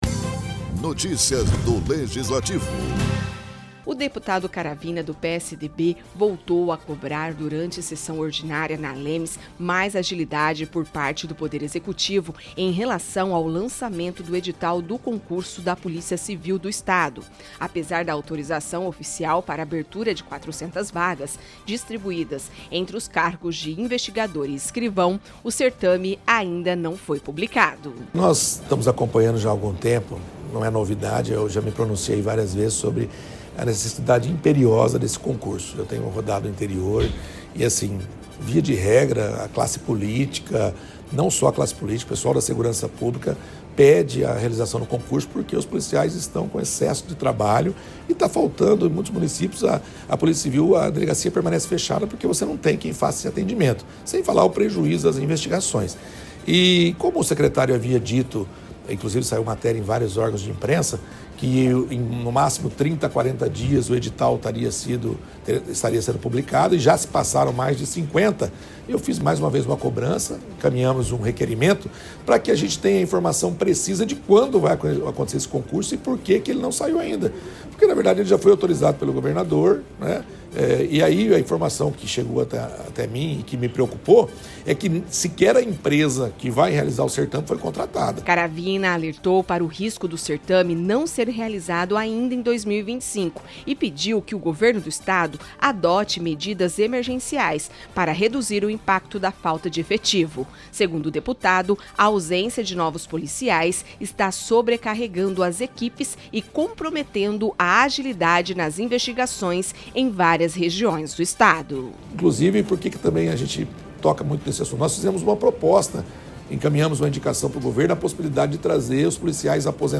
Durante sessão na ALEMS, o deputado Pedro Caravina cobrou agilidade no lançamento do concurso da Polícia Civil. Mesmo com 400 vagas autorizadas, o edital ainda não foi publicado pelo Governo do Estado. Parlamentar teme que o certame não ocorra este ano e pede medidas emergenciais para reforçar o efetivo.